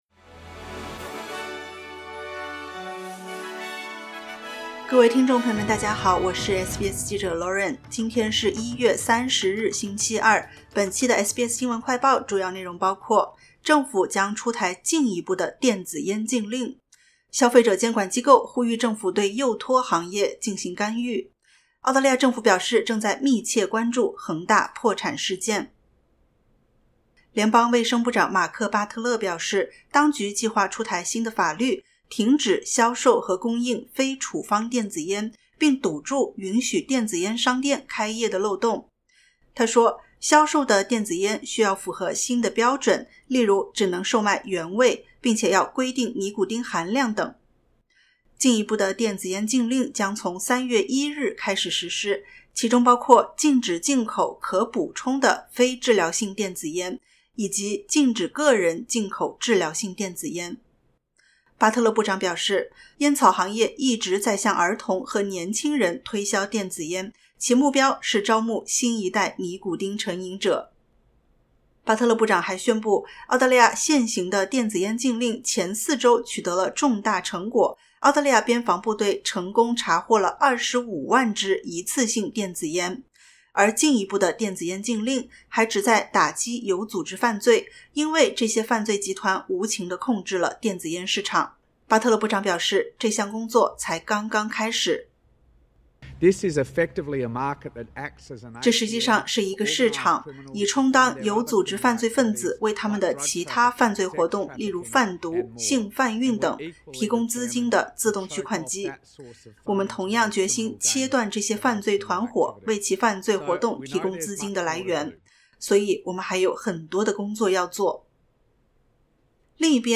【SBS新闻快报】政府将出台进一步电子烟禁令 禁止非处方电子烟销售